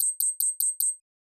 Knock Notification 6.wav